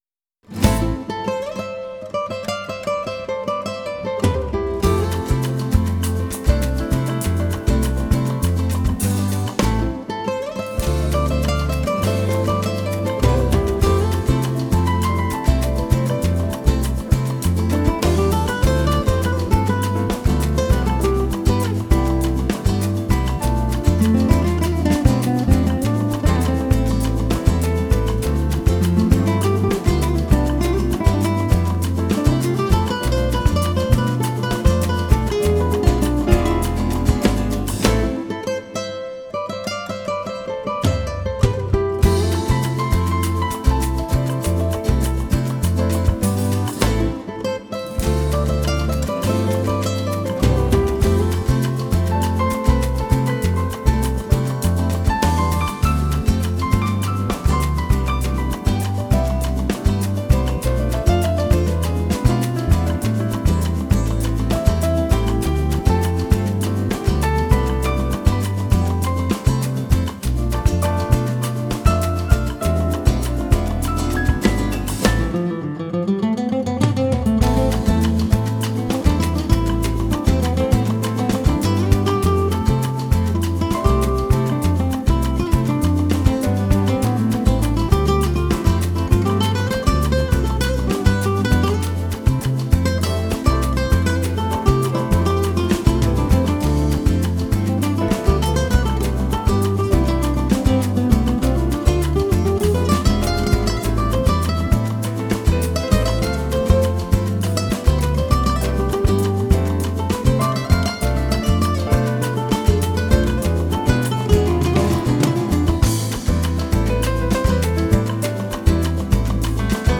Genre: Instrumental, Latin, New Age